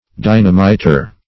Dynamiter \Dy"na*mi`ter\, n.